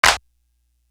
Bring You Light Clap.wav